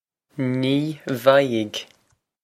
Pronunciation for how to say
Nee vie-g
This is an approximate phonetic pronunciation of the phrase.